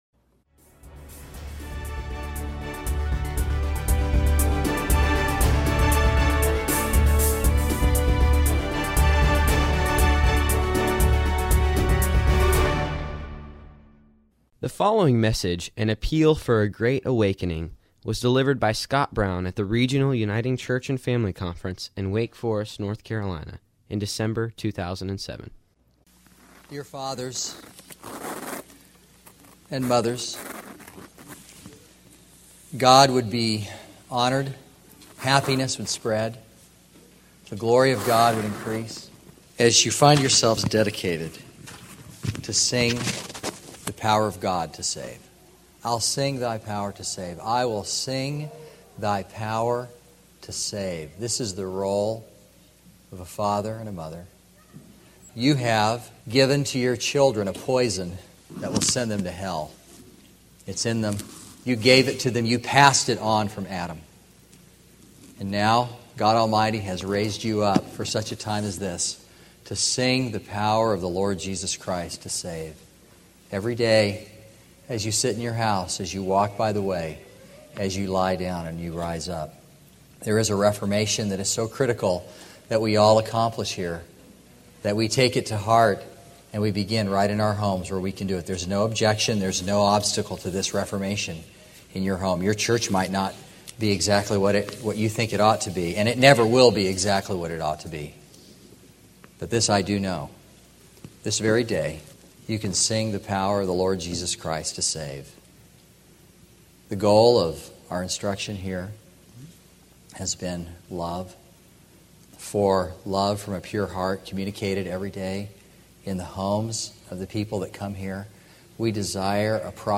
at the Regional Uniting Church and Family Conference in Wake Forest, North Carolina, in December 2007